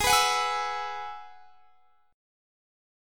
Listen to G#m7#5 strummed